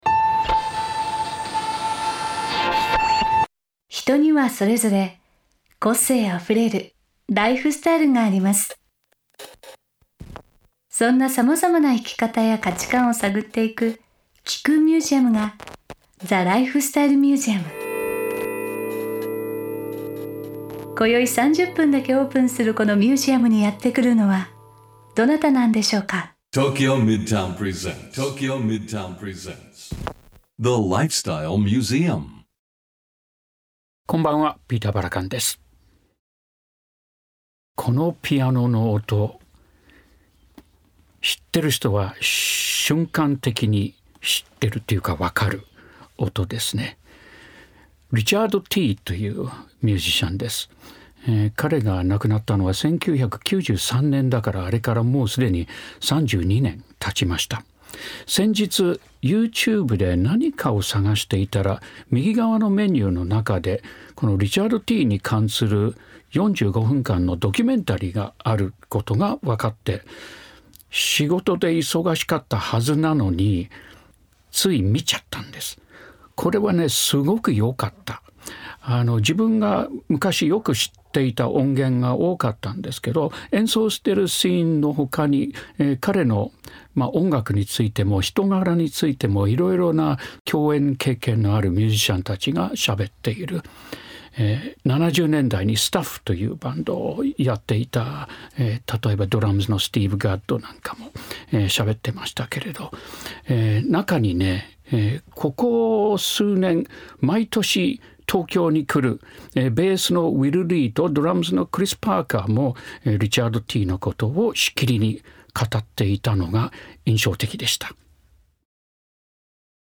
ピーター・バラカン氏がメインパーソナリティーとなり、毎回様々なゲストを迎えて生き方や価値観を探っていくゲストトーク番組。